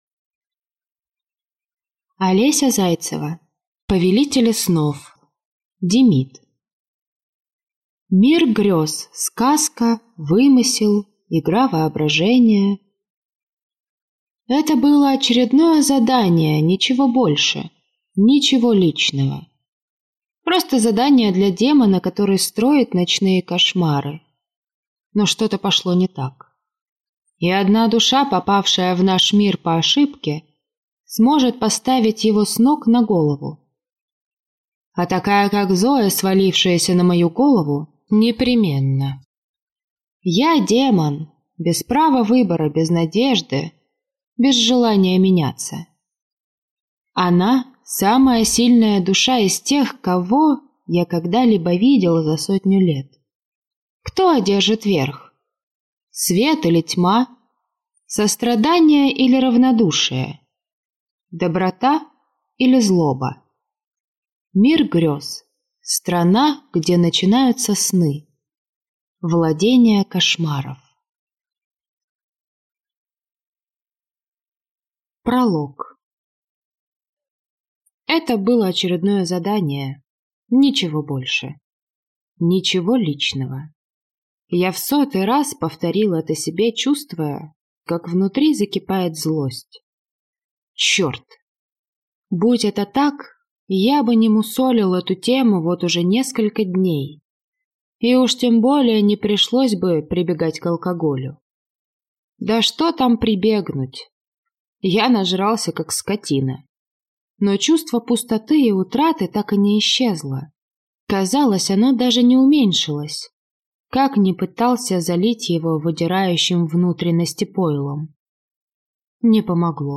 Аудиокнига Повелители Снов. Демид | Библиотека аудиокниг